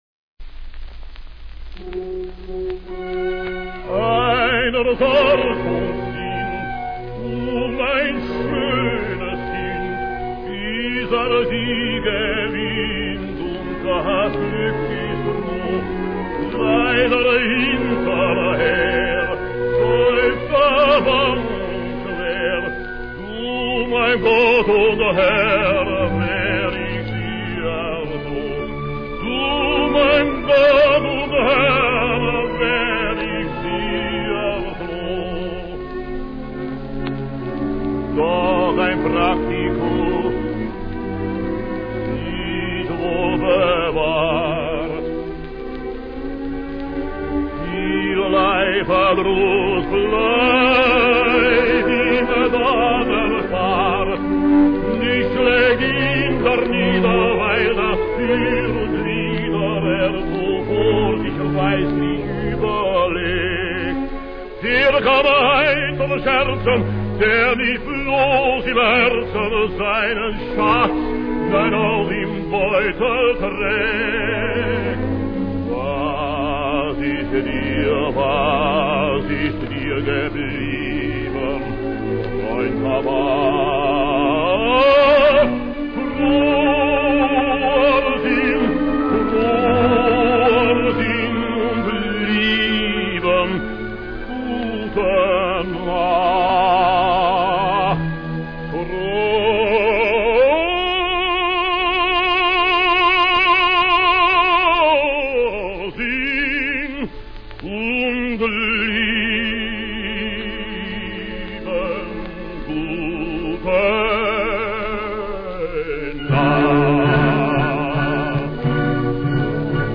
Joseph Schmidt und Michael Bohnen singen hier das Duett "Kezal - Hans" aus der Verkauften Braut.